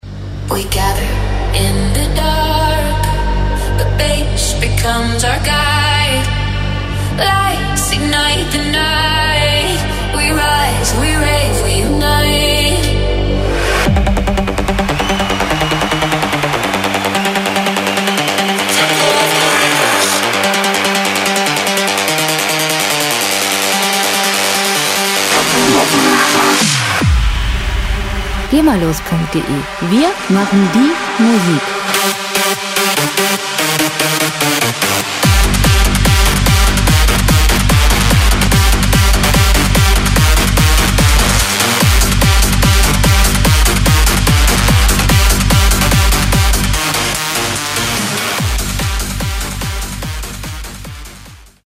Musikstil: Techno
Tempo: 128 bpm
Tonart: G-Moll
Charakter: euphorisch, ekstatisch
Instrumentierung: Synthesizer, Popsängerin